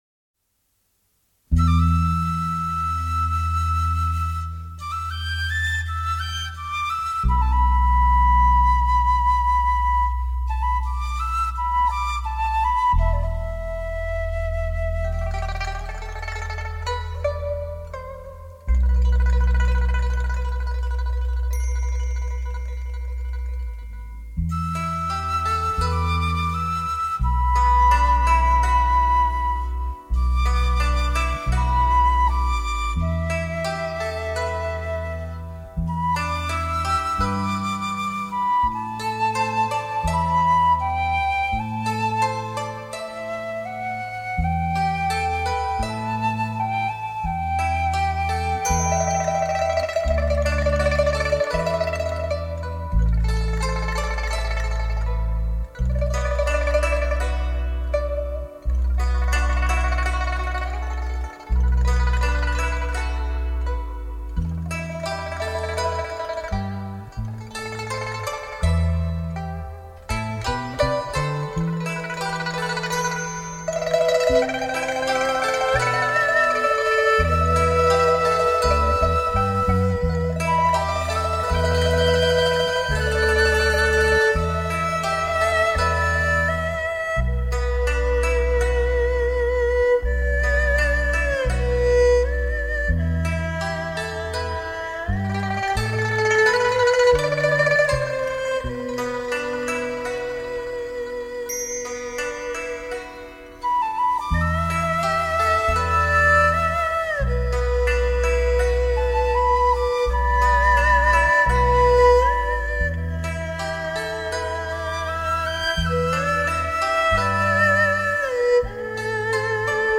将100KHz宽频带/24bit音频信息载入
音色更接近模拟(Analogue)声效
强劲动态音效中横溢出细致韵味